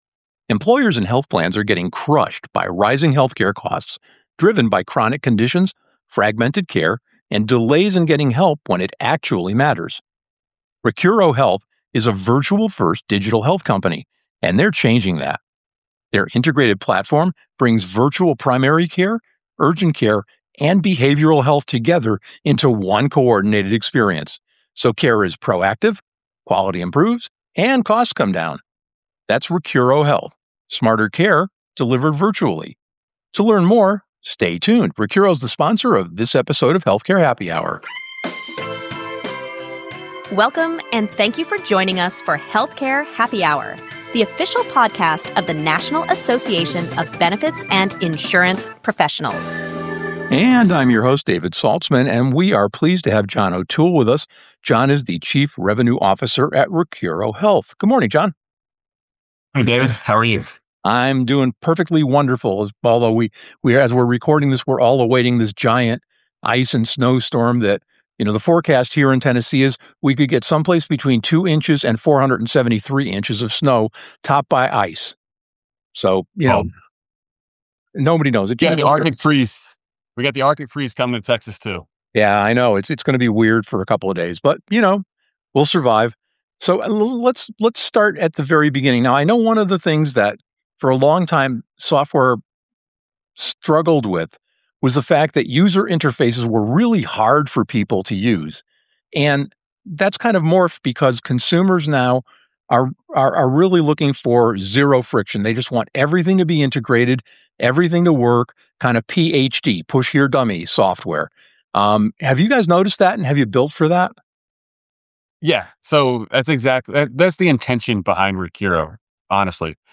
Drawing from industry data, case study insights, and market trends, the conversation explores how coordinated virtual care delivery can improve care quality while generating measurable cost savings across populations.